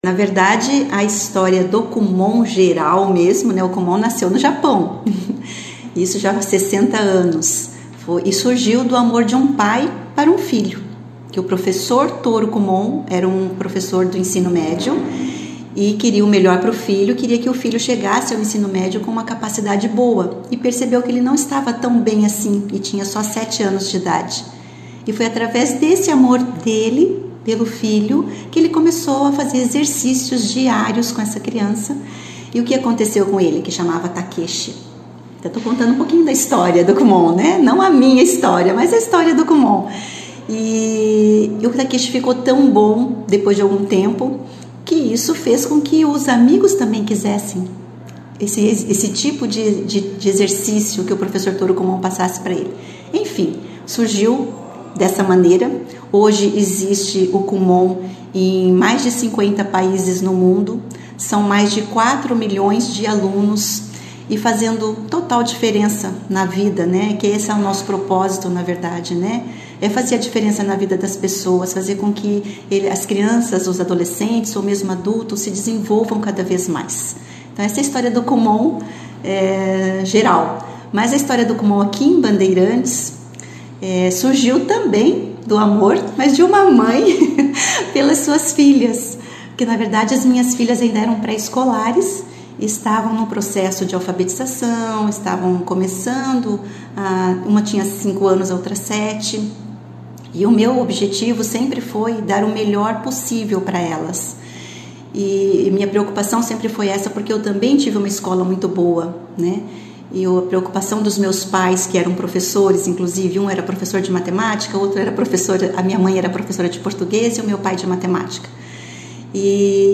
participou da 2ª edição do jornal Operação Cidade